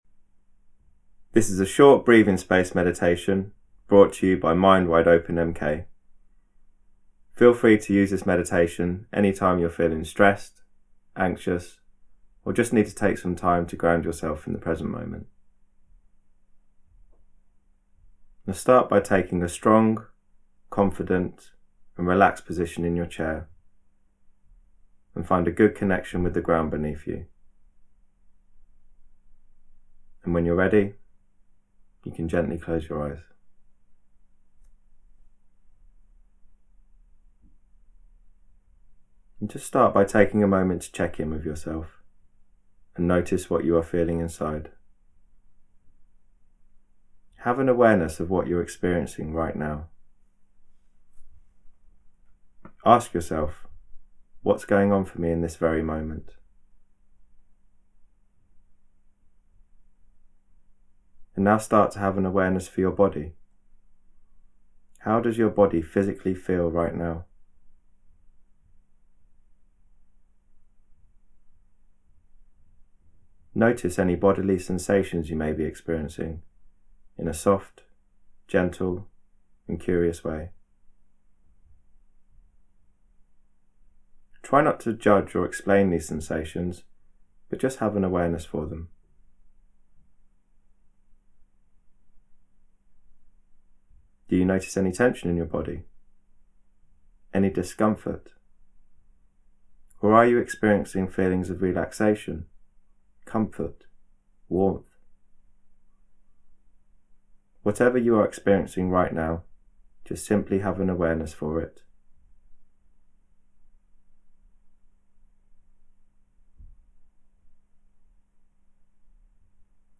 Here is a free sample of an audio guided meditation